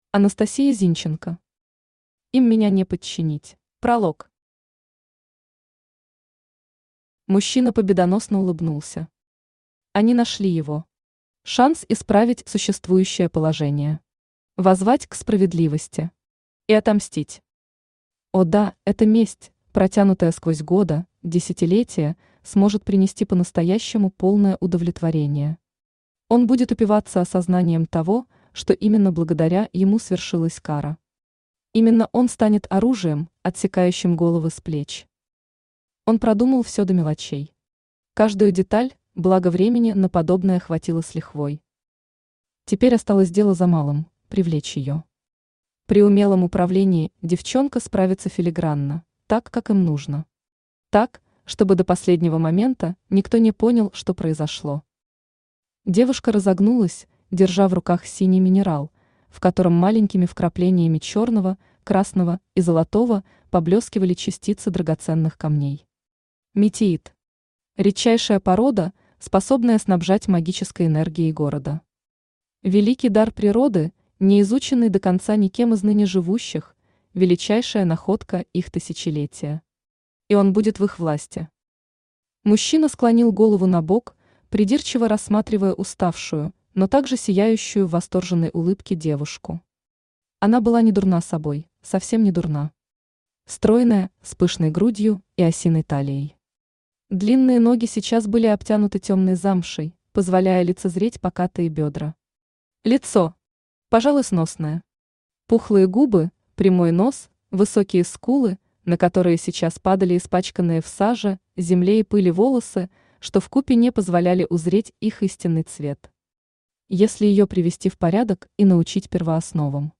Аудиокнига Им меня не подчинить!
Aудиокнига Им меня не подчинить! Автор Анастасия Зинченко Читает аудиокнигу Авточтец ЛитРес.